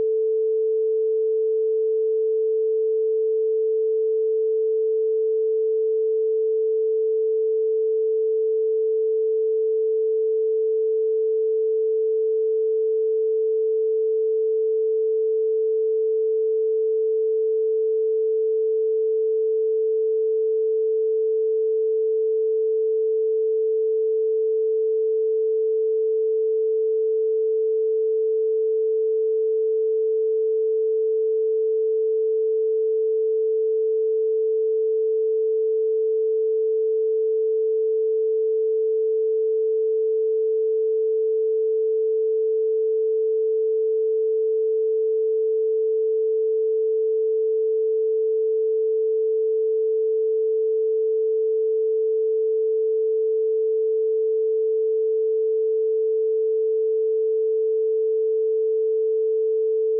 test-tone.wav